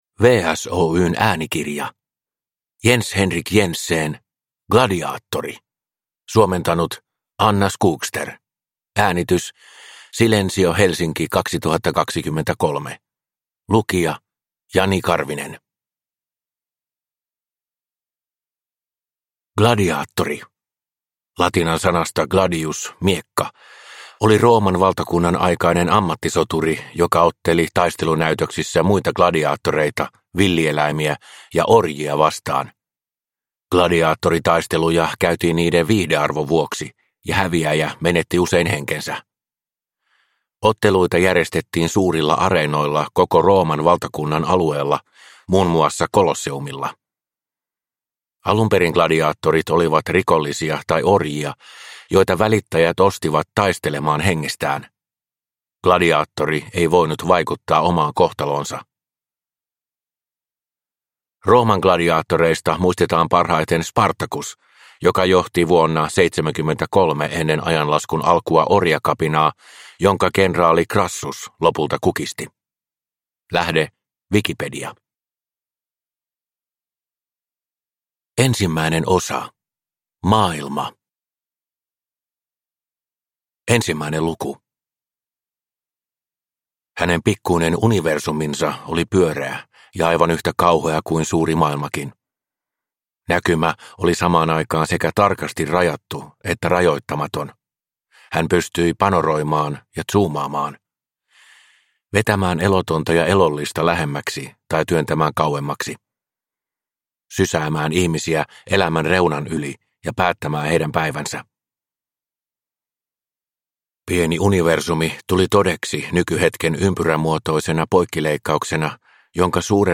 Gladiaattori – Ljudbok – Laddas ner